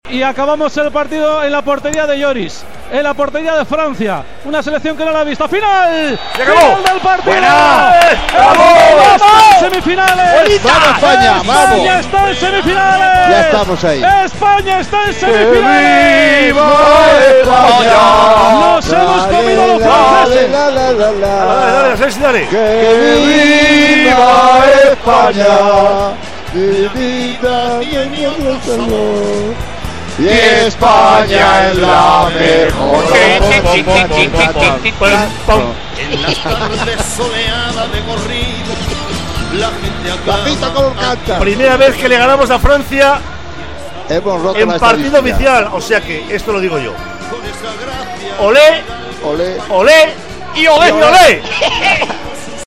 Espanya guanya per 2 a 0 a França i passa a semifinals. Cant de la cançó "Viva España".
Esportiu